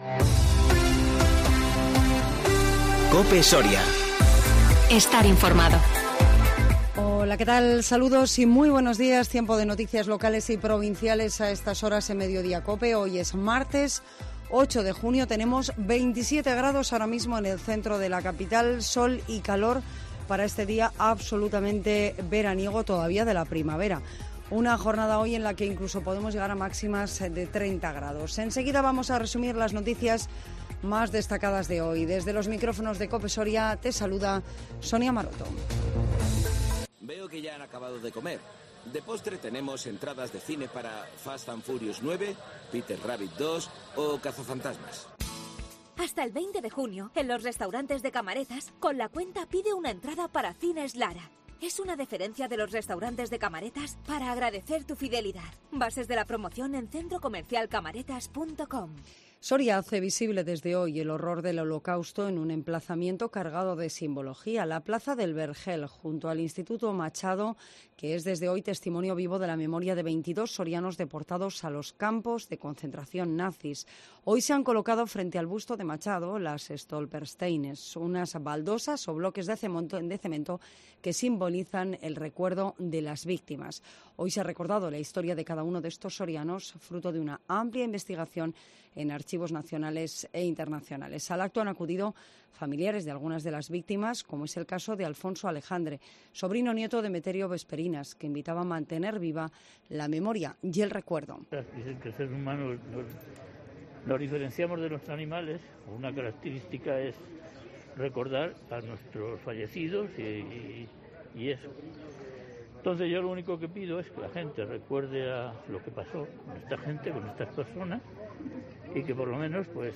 INFORMATIVO MEDIODÍA 8 JUNIO 2021